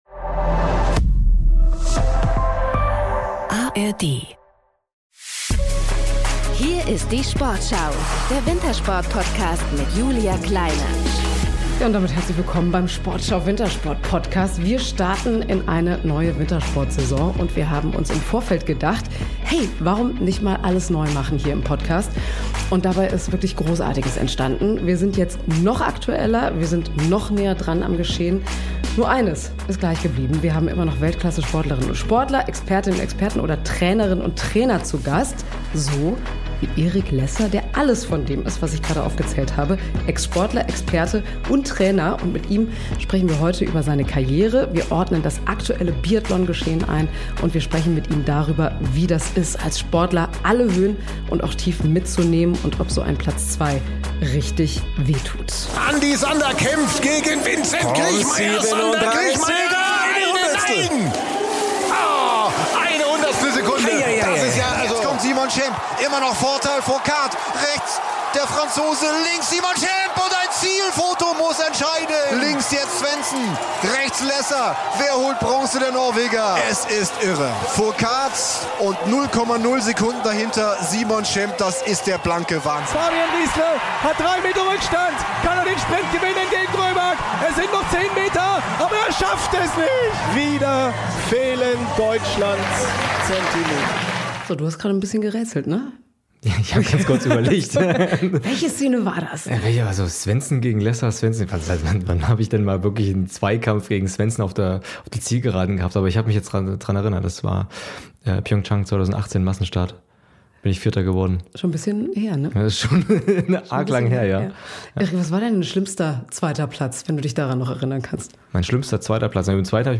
(00:29:00) Interview